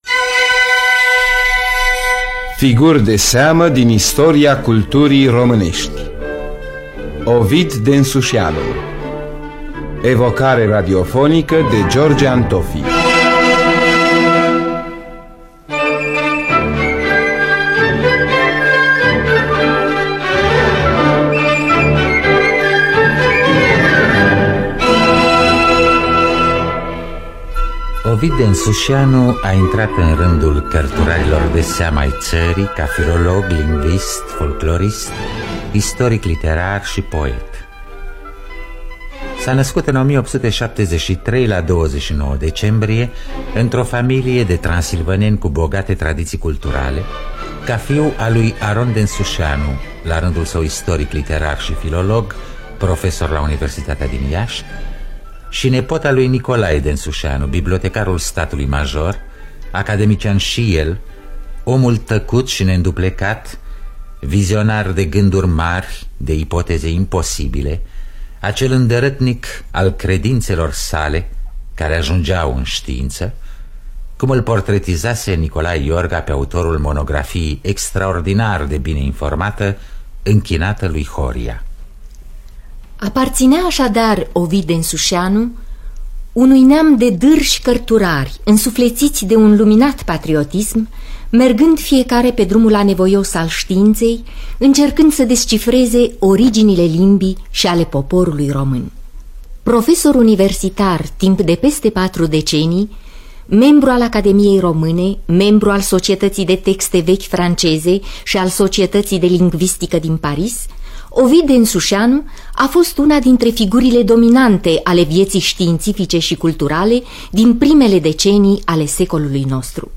Biografii, memorii: Ovid Densușianu.